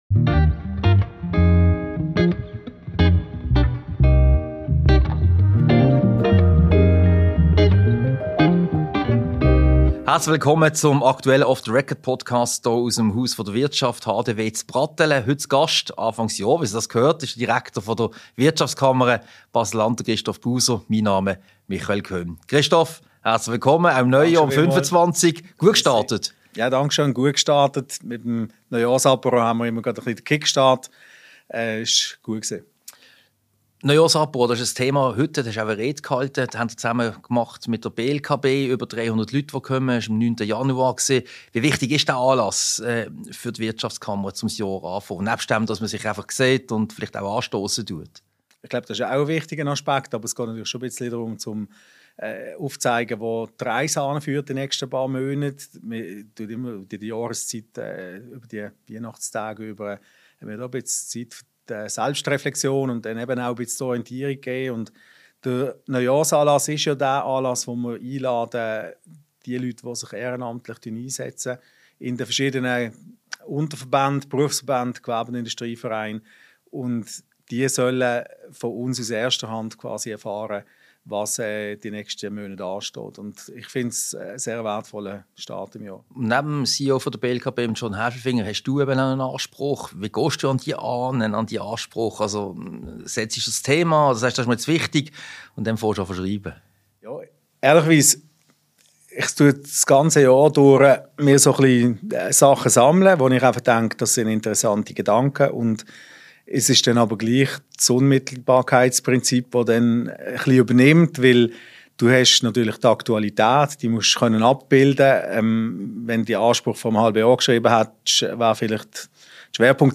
Diese Podcast-Ausgabe wurde als Video-Podcast im Multimedia-Studio der IWF AG im Haus der Wirtschaft HDW aufgezeichnet.